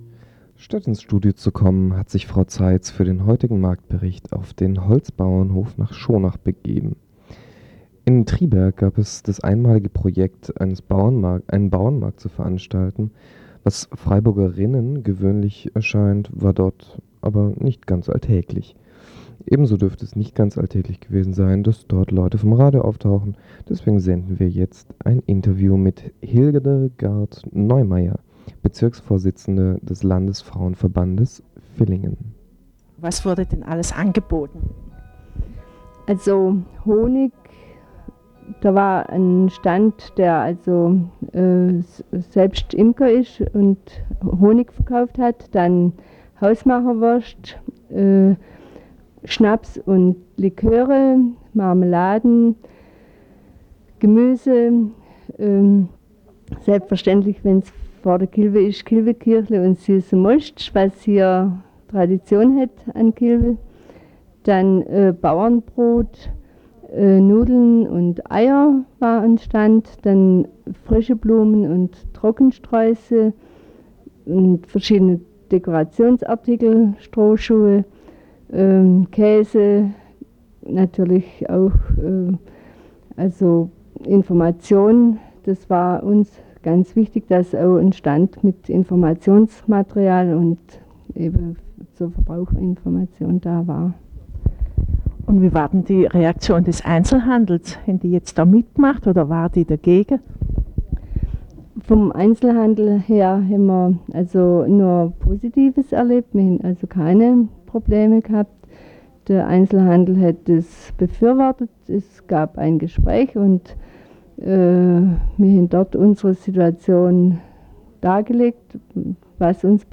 Landfrauentag zu Selbstvermarktung auf Bauernmarkt -Marktbericht aus Triberg